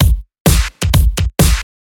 Electrohouse Loop 128 BPM (1).wav